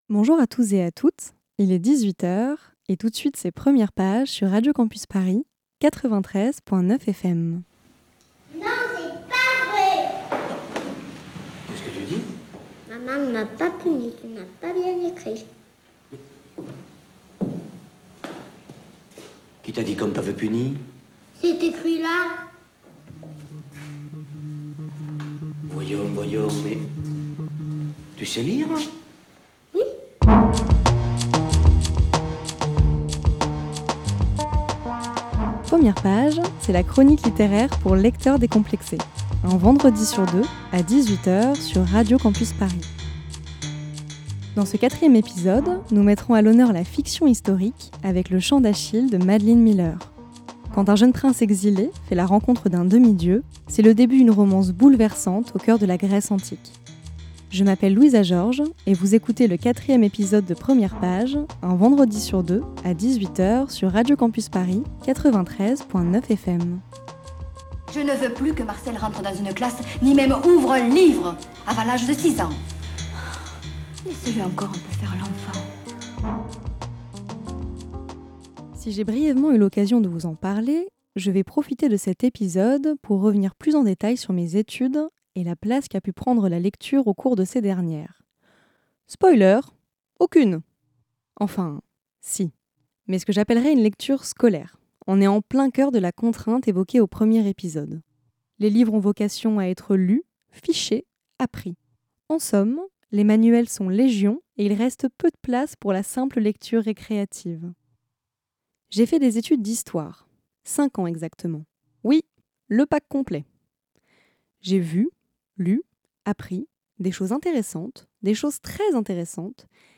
Voici en podcast le quatrième épisode de Premières Pages, une chronique littéraire pour lecteur.trice.s décompléxé.e.s, un vendredi sur deux sur Radio Campus Paris.